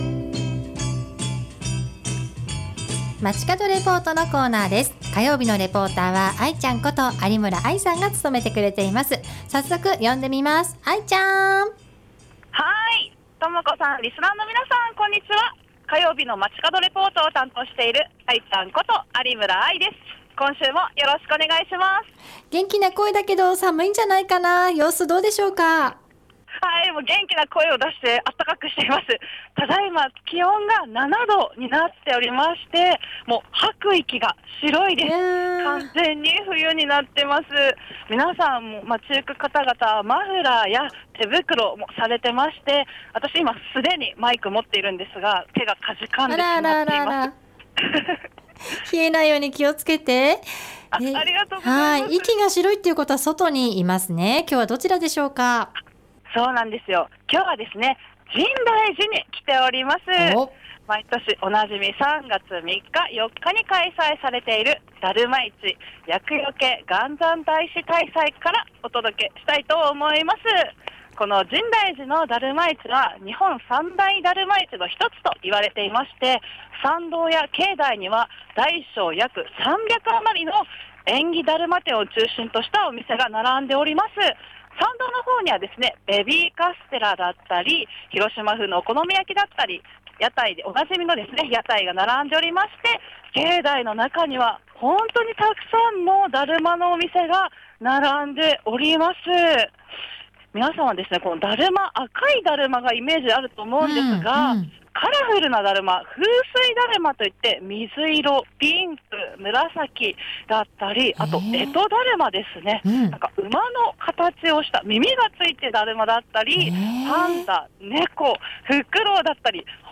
午後のカフェテラス 街角レポート
ということで、今週は深大寺の「だるま市・厄除け元三大師大祭」からお届けしました。